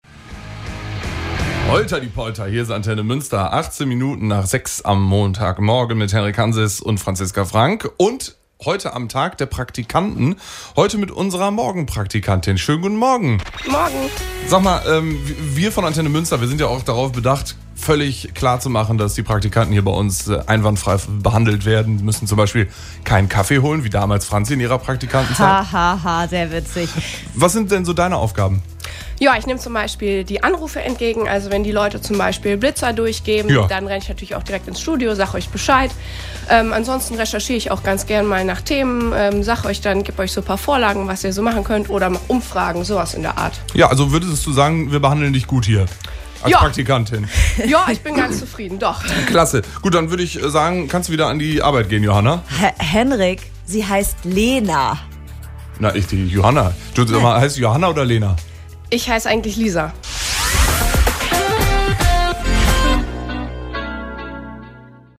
Kollegengespräch-zum-Praktikantentag.mp3